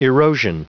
Prononciation du mot erosion en anglais (fichier audio)